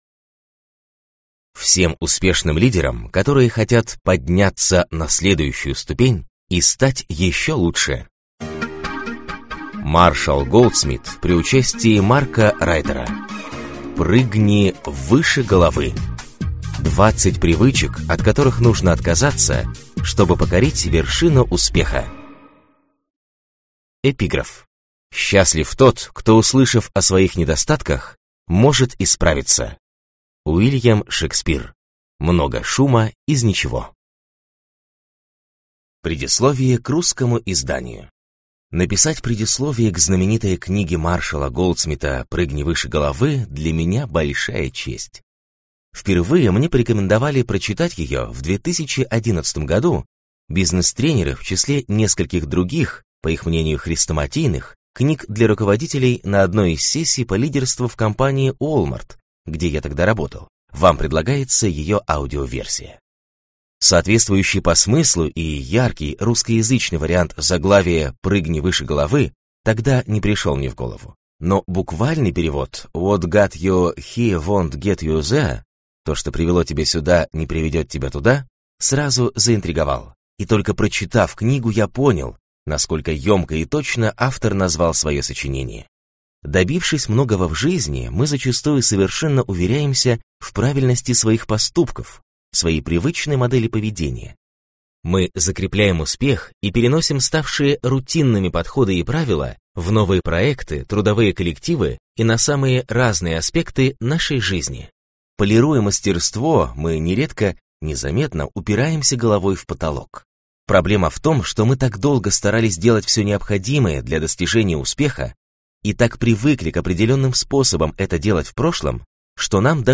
Аудиокнига Прыгни выше головы!